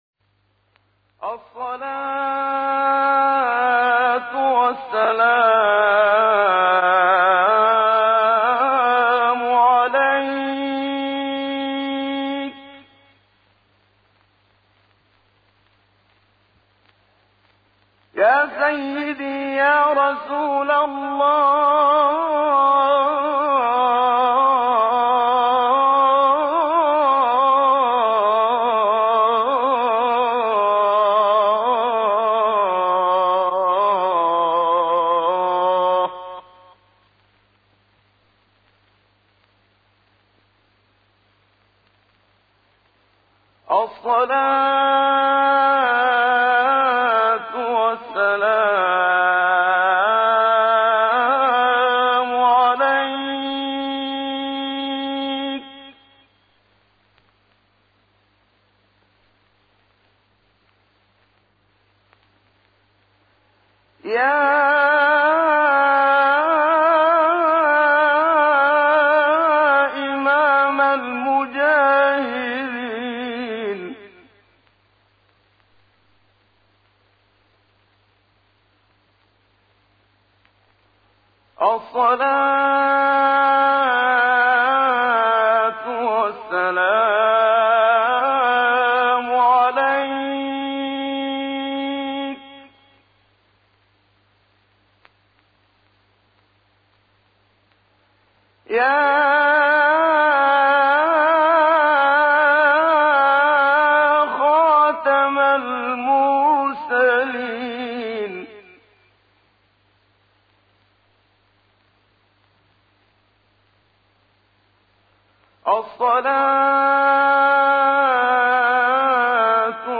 ابتهال استاد منشاوی.mp3
ابتهال-استاد-منشاوی.mp3